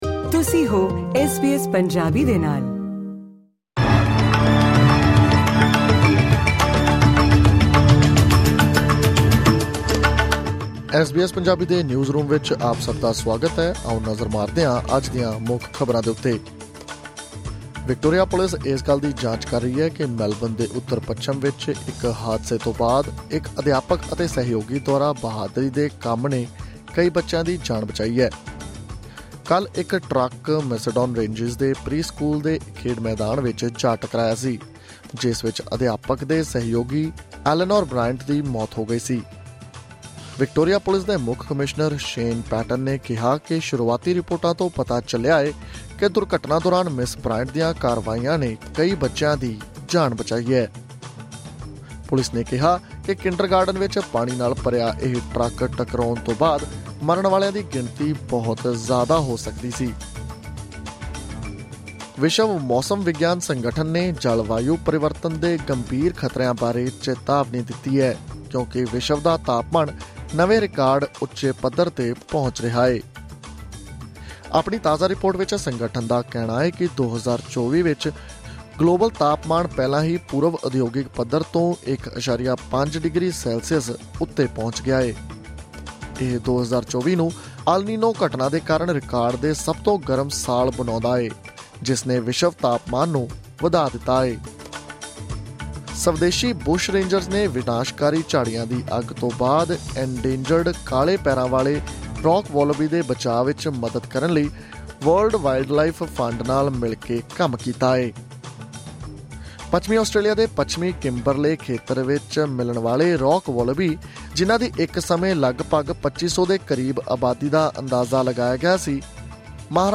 ਐਸ ਬੀ ਐਸ ਪੰਜਾਬੀ ਤੋਂ ਆਸਟ੍ਰੇਲੀਆ ਦੀਆਂ ਮੁੱਖ ਖ਼ਬਰਾਂ: 12 ਨਵੰਬਰ 2024